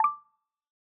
notification_simple-01.ogg